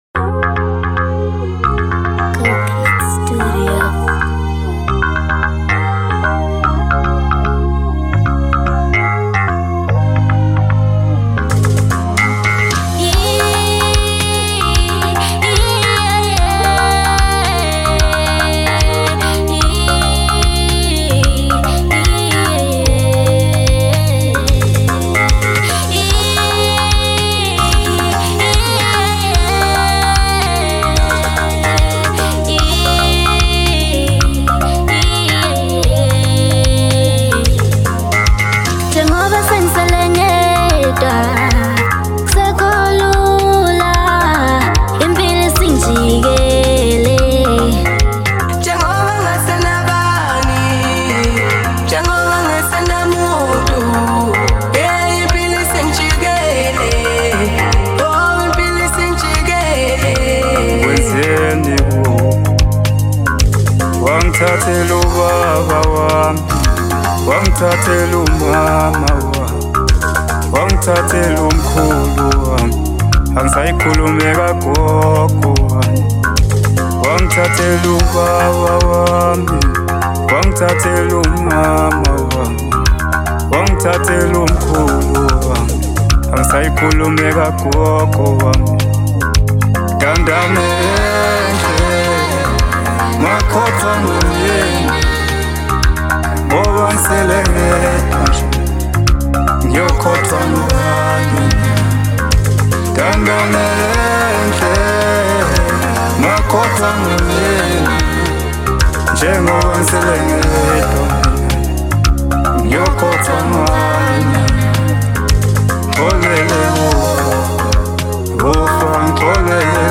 Genre : Maskandi